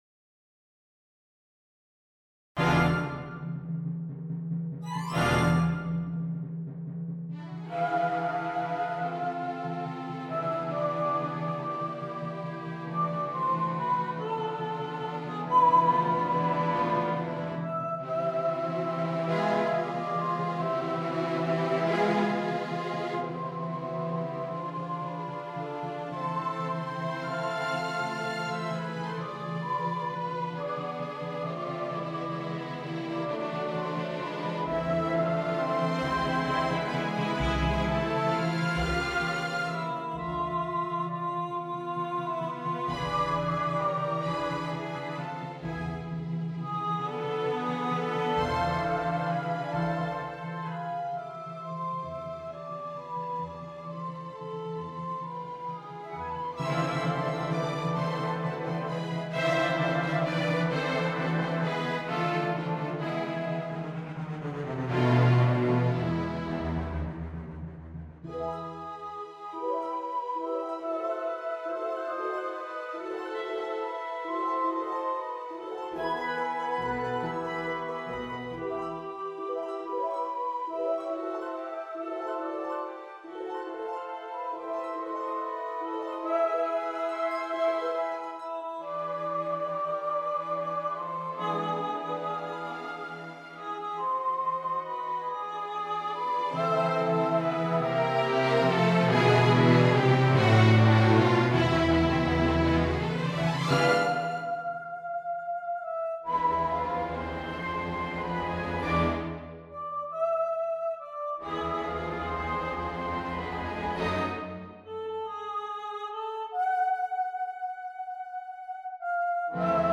Timpani
Harp
Organ